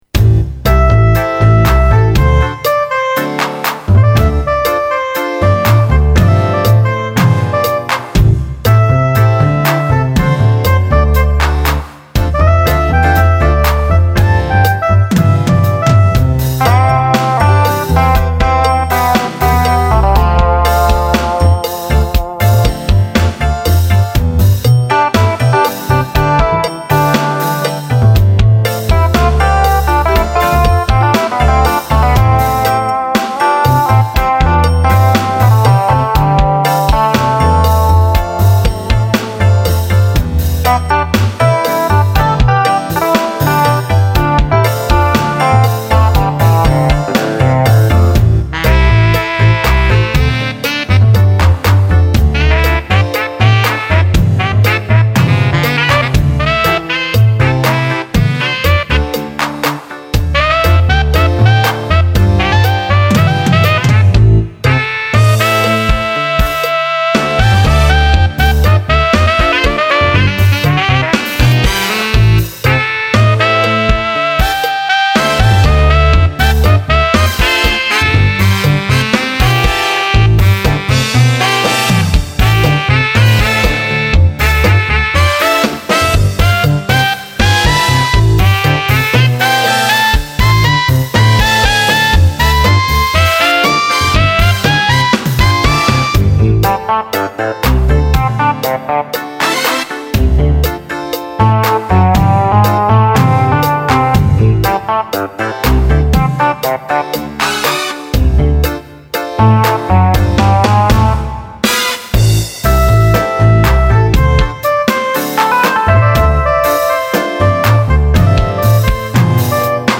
ギターとサックスの組み合わせがこの頃から定着。
特にサックスのアドリブが、お気に入り。